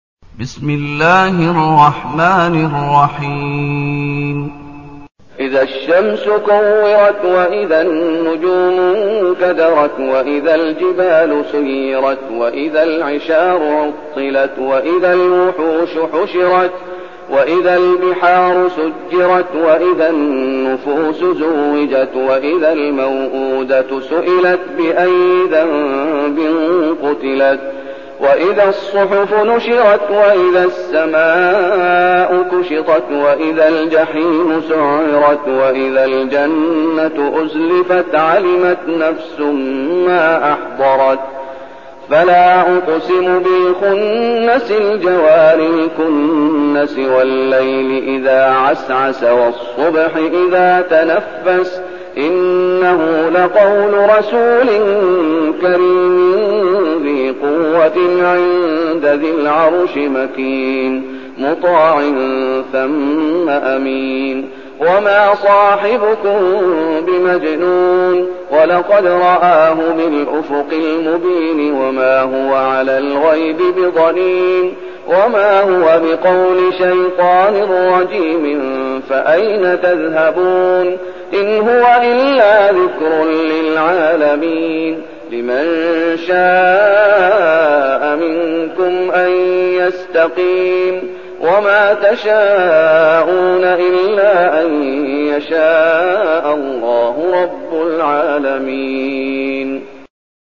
المكان: المسجد النبوي الشيخ: فضيلة الشيخ محمد أيوب فضيلة الشيخ محمد أيوب التكوير